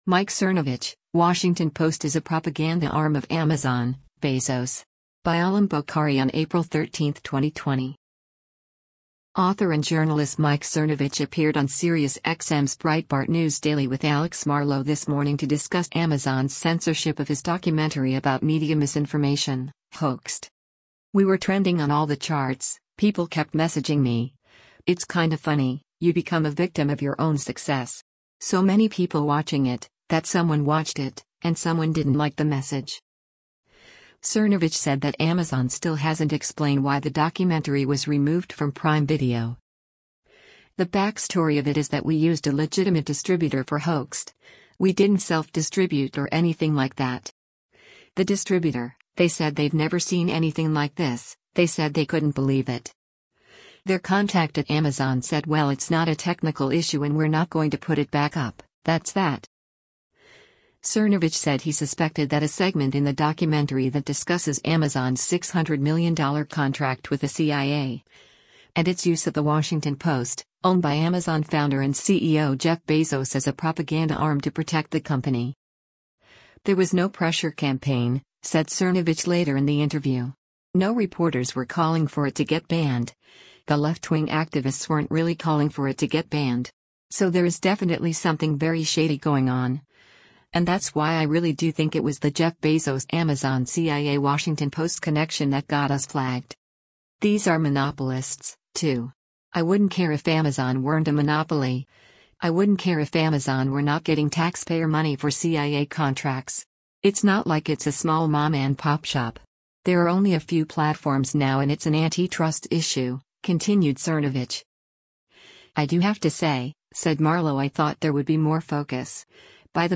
Author and journalist Mike Cernovich appeared on SiriusXM’s Breitbart News Daily with Alex Marlow this morning to discuss Amazon’s censorship of his documentary about media misinformation, Hoaxed.